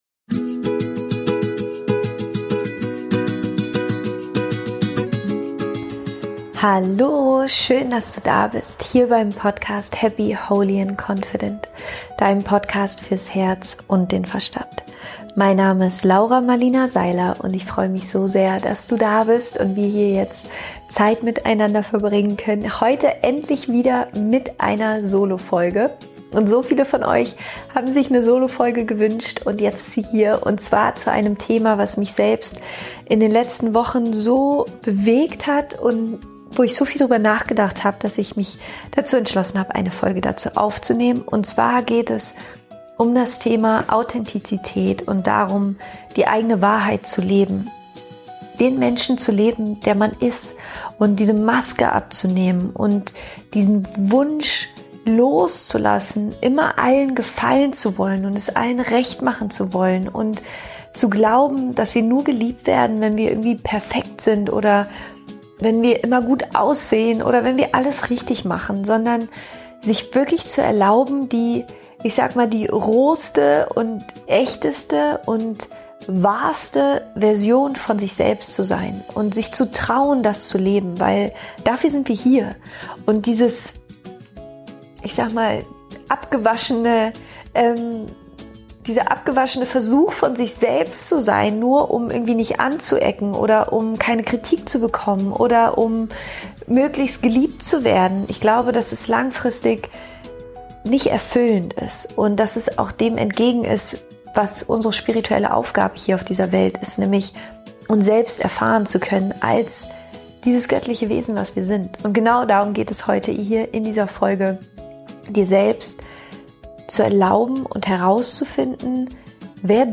Dieses Mal teile ich eine Solofolge mit dir, in der es um ein Thema geht, das mich selbst in den letzten Wochen sehr bewegt hat und über das ich viel nachgedacht habe.